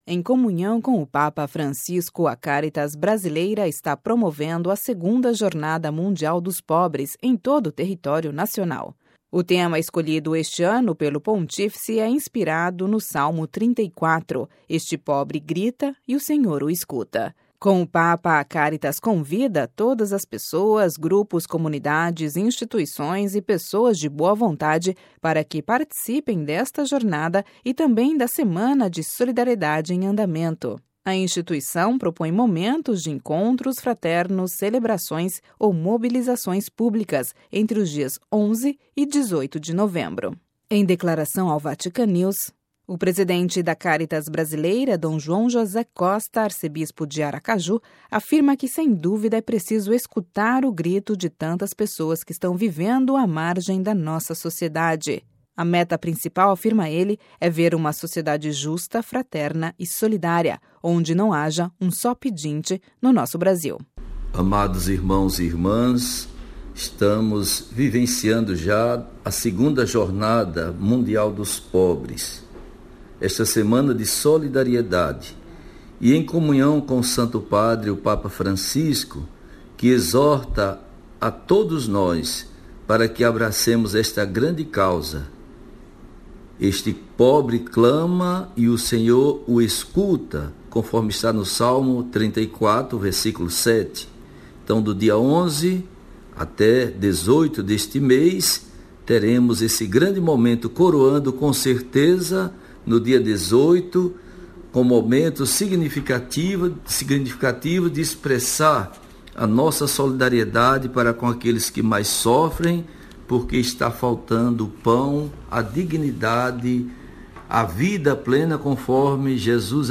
Em declaração ao Vatican News-Rádio Vaticano, o presidente da Cáritas Brasileira, Dom João José Costa, arcebispo de Aracaju (SE), afirma que, sem dúvida, é preciso escutar o grito de tantas pessoas que estão vivendo à margem da sociedade.